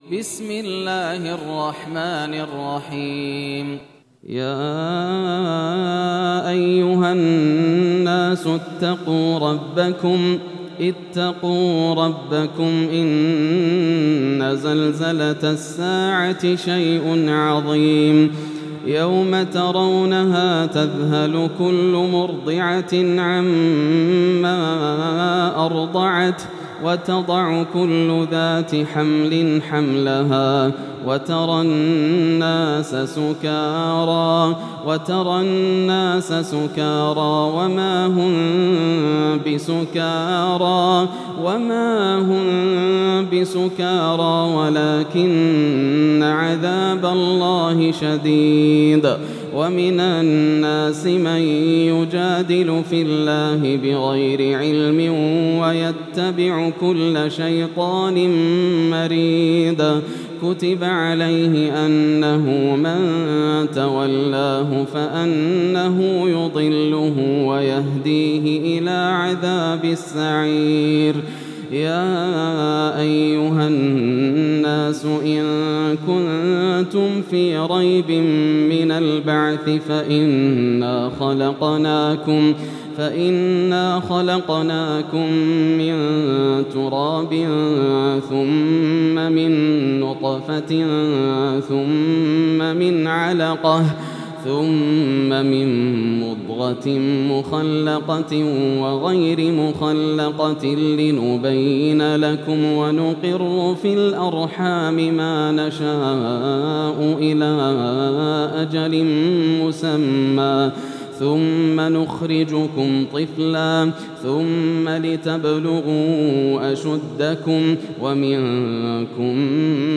سورة الحج > السور المكتملة > رمضان 1433 هـ > التراويح - تلاوات ياسر الدوسري